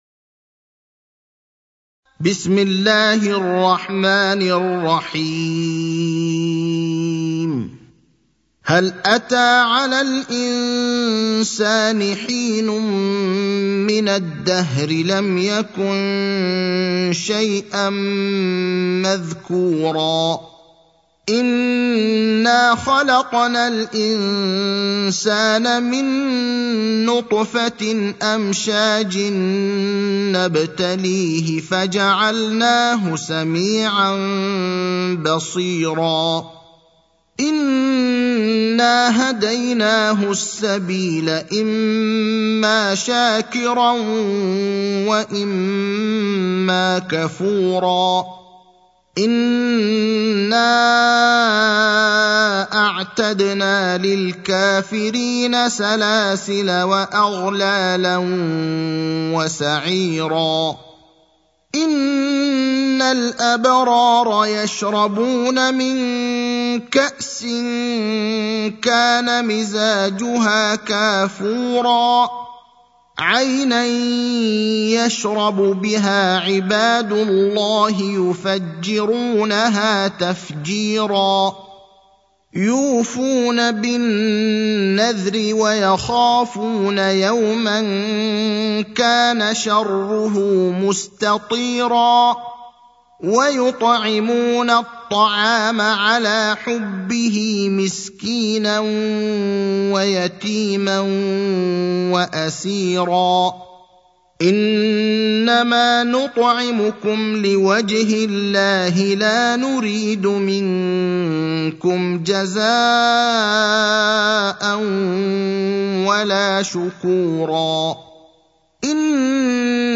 المكان: المسجد النبوي الشيخ: فضيلة الشيخ إبراهيم الأخضر فضيلة الشيخ إبراهيم الأخضر الإنسان (76)